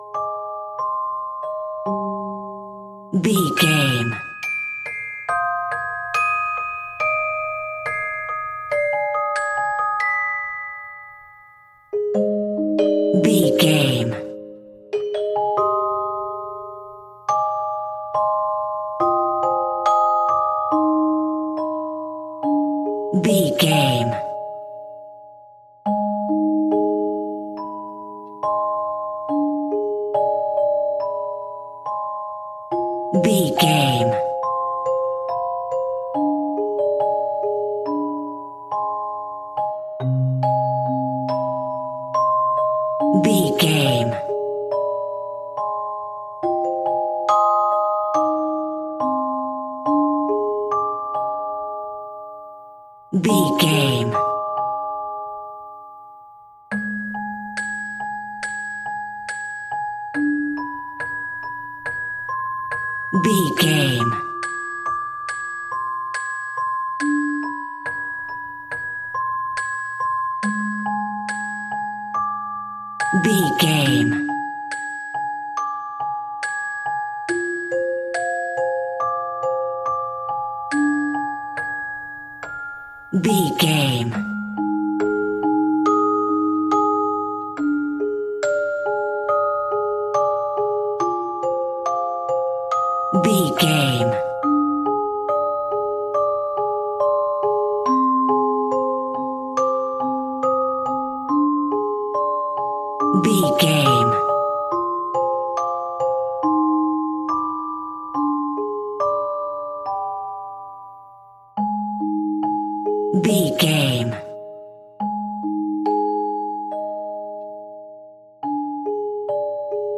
Ionian/Major
A♭
scary
ominous
dark
suspense
haunting
eerie
percussion
horror
creepy
spooky